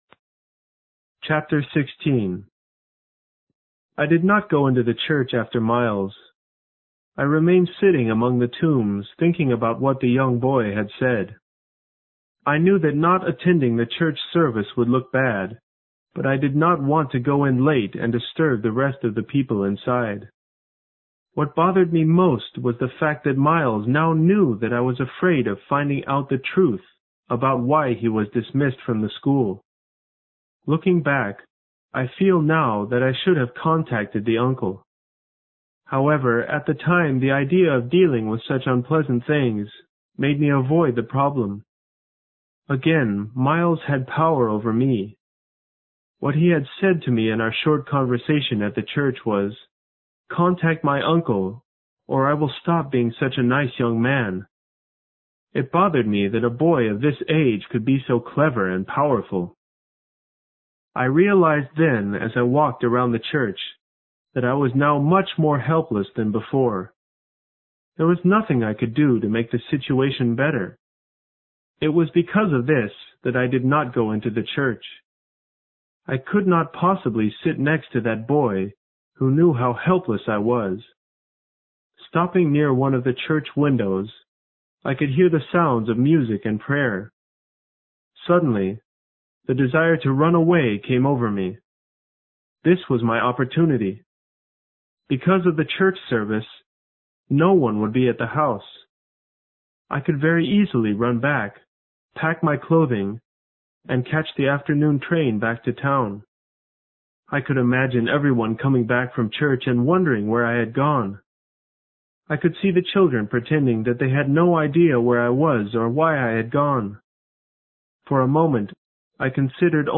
有声名著之螺丝在拧紧chapter16 听力文件下载—在线英语听力室